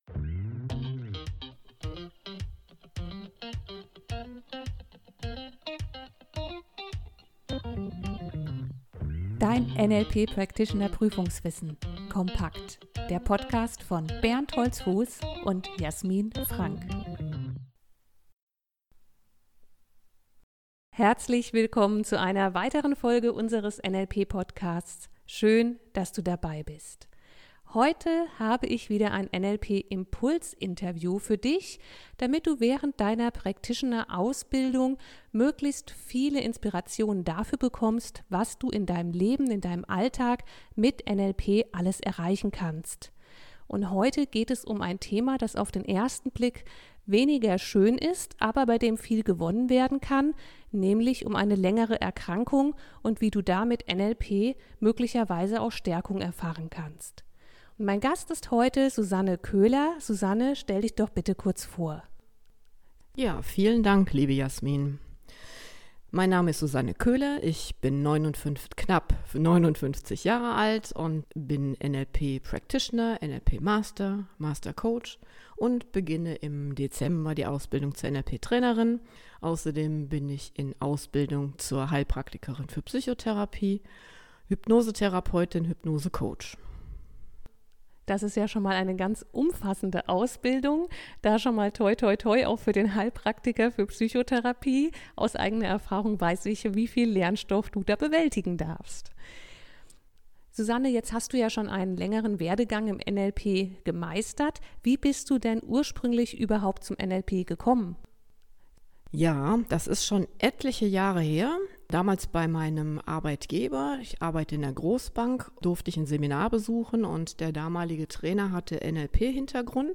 NLP Interview